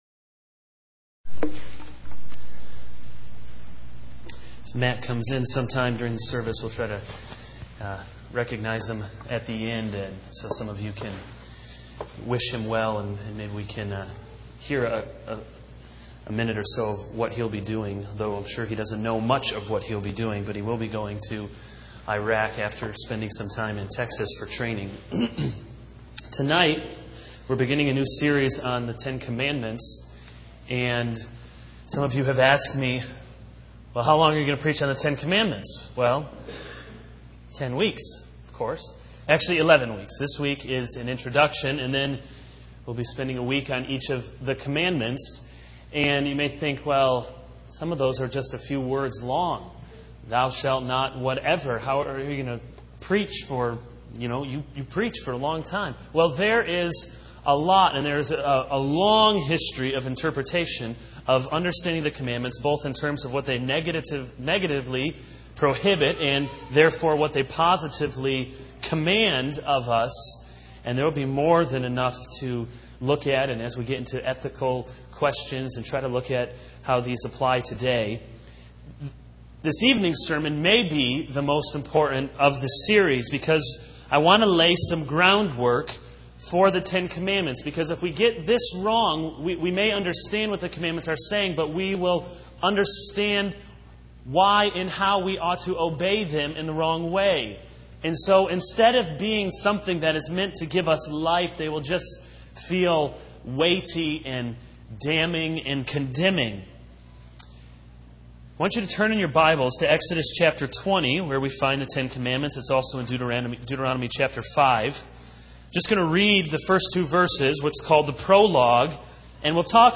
This is a sermon on Exodus 20:1-17 - The role of law in the Christian life.